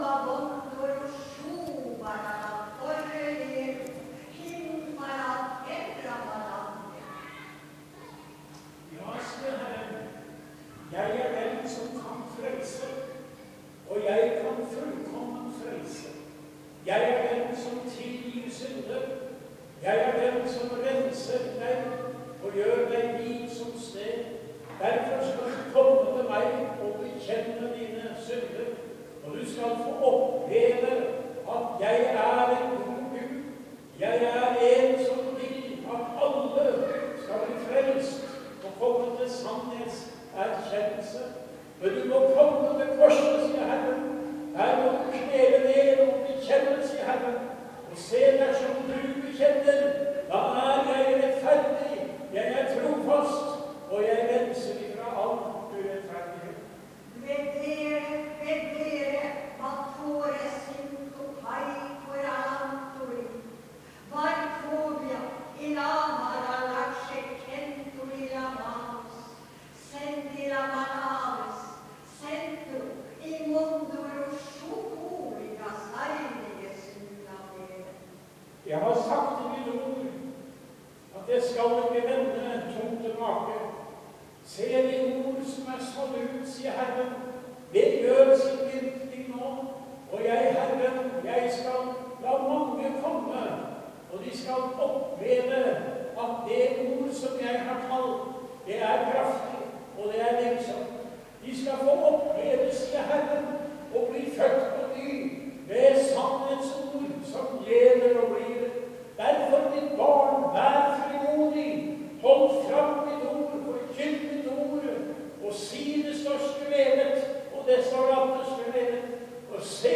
Møte i Maranata 16.2.2014.
Tale
Tungetale